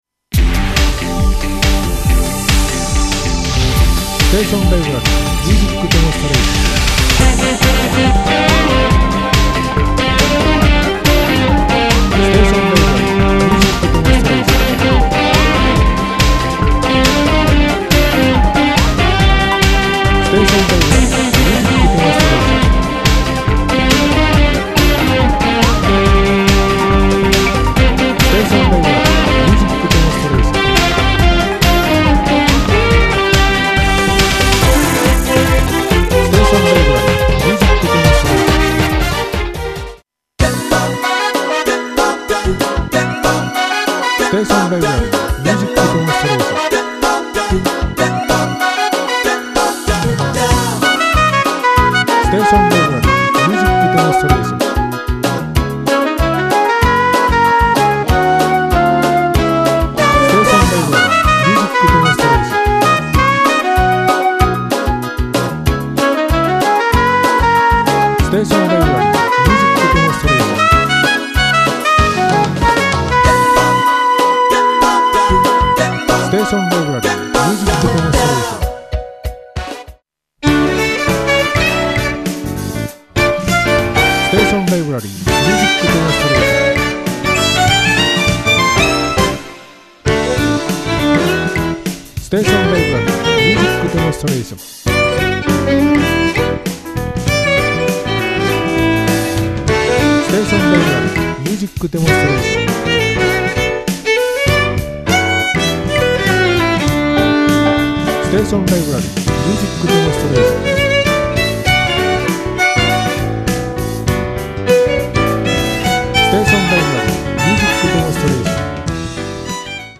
ＢＧＭで一番需要の多い、軽快で活発、そして楽しい雰囲気の楽曲を特集しています。バラエティー豊かにお送りしています。